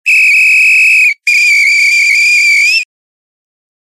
警察が違反の取り締まりをしているときに使用しているような笛の音。
この笛の正式名称は呼子笛（よびこぶえ）と言って音を柔らかくするために中にコルクの球が入っています。